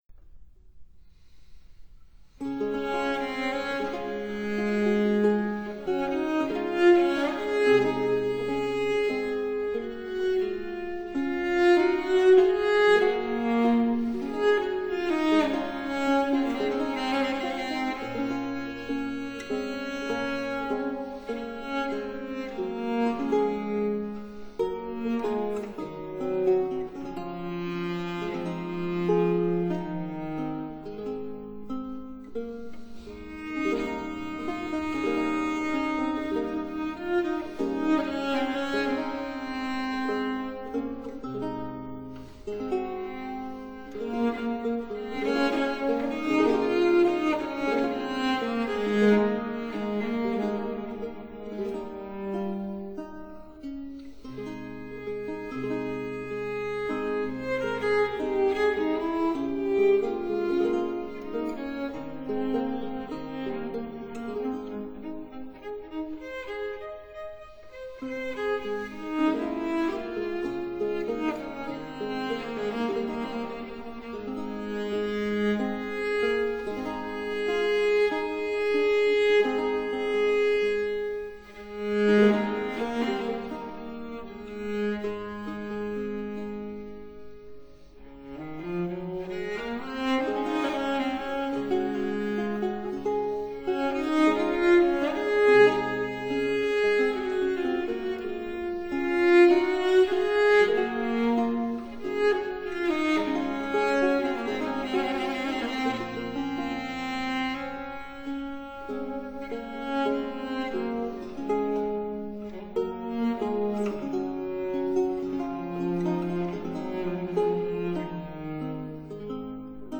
Cello Sonata, Op. 1 No. 1 (1766)
Cellos
(Period Instruments)